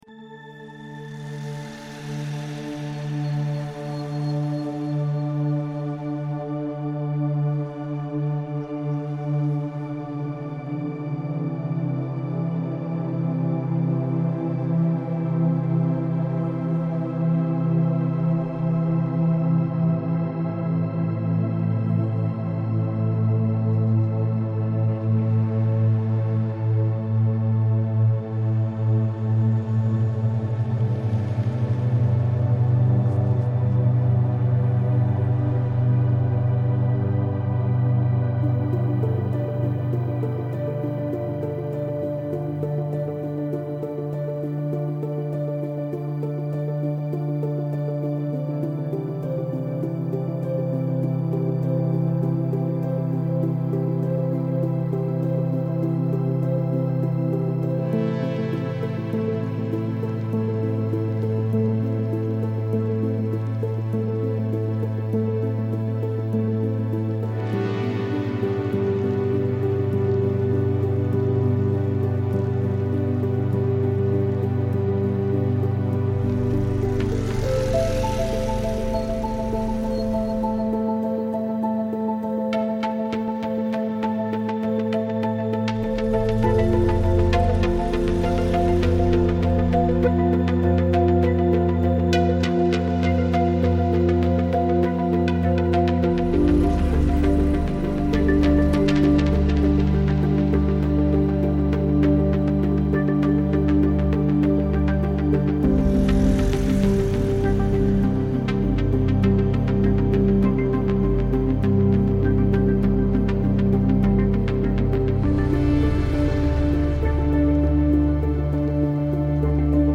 The most striking feature of the original field recording is dozens of motorbikes and scooters zooming past and dodging their way through the pedestrians. Throughout the day when it's busier they can only pass very slowly, but with sparser crowds at night they rip past us on both sides.